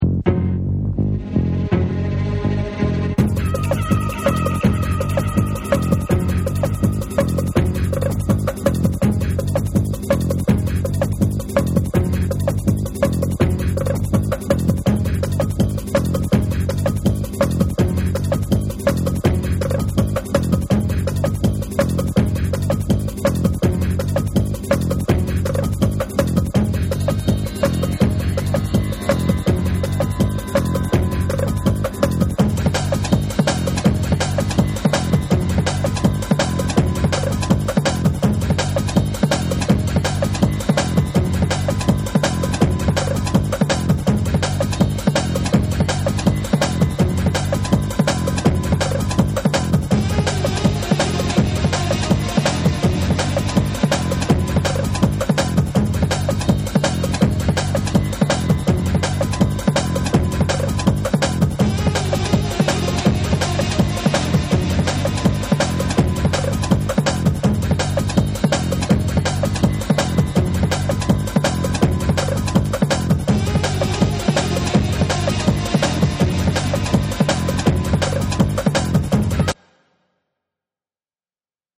倍の早さでリワークしたSAMPLE2も収録。
TECHNO & HOUSE / ORGANIC GROOVE